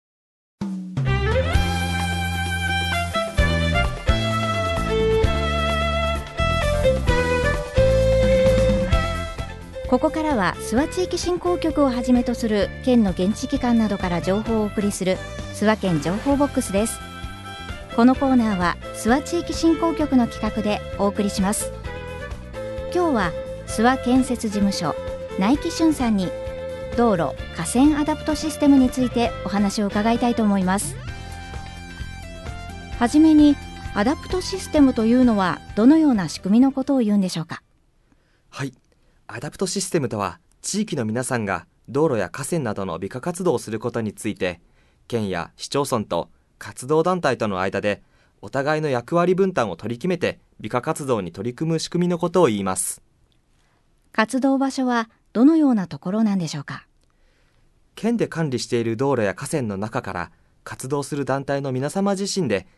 コミュニティエフエムを活用した地域情報の発信